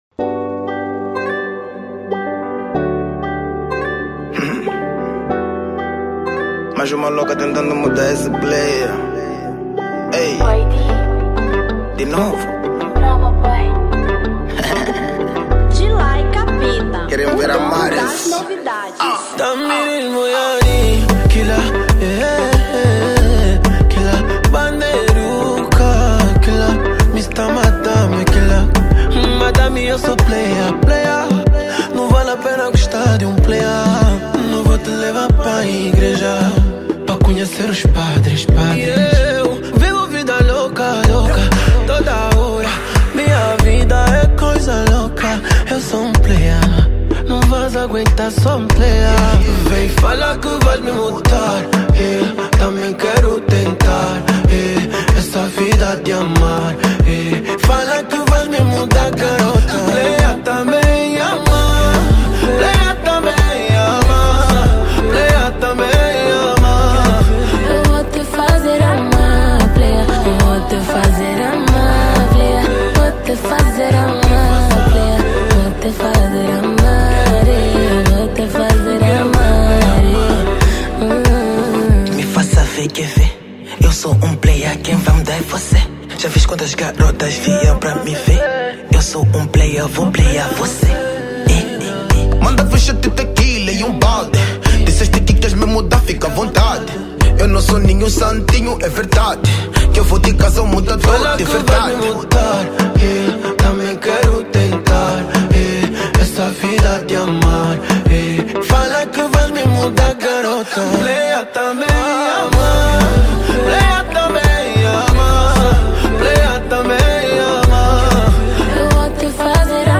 Kizomba 2023